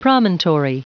Prononciation du mot promontory en anglais (fichier audio)
Prononciation du mot : promontory